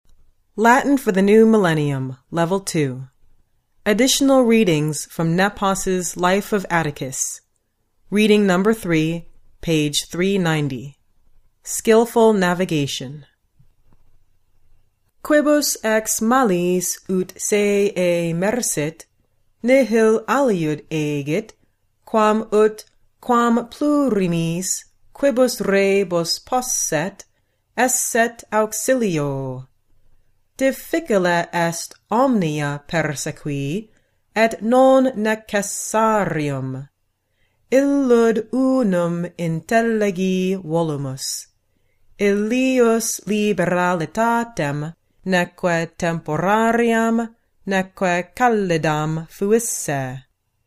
provide a professionally recorded reading in the restored classical pronunciation of Latin.